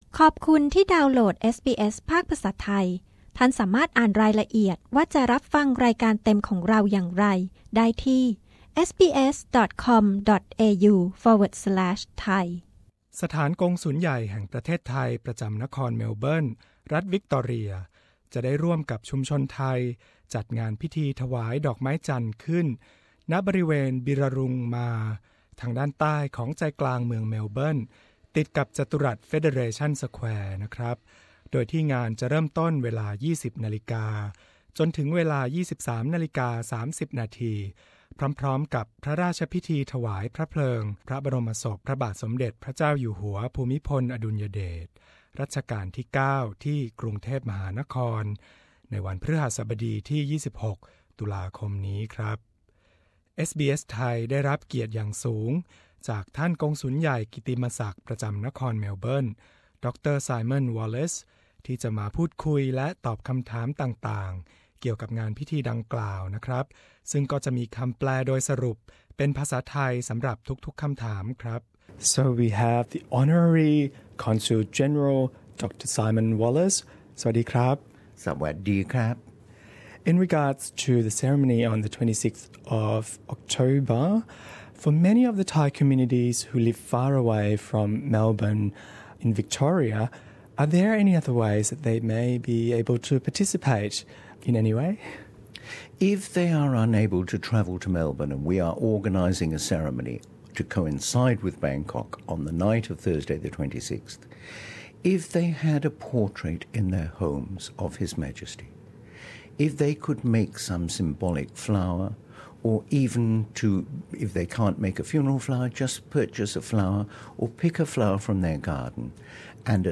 ท่านกงสุลใหญ่ไทยกิตติมศักดิ์ ประจำนครเมลเบิร์น ดร. ไซมอน วอลเลซ ชี้แจงรายละเอียดต่างๆ เกี่ยวกับพิธีถวายดอกไม้จันทน์ พร้อมกันกับงานพระราชพิธีถวายพระเพลิงพระบรมศพในหลวงรัชกาลที่ 9 วันที่ 26 ตุลาคม ณ บิร์รารุง มาร์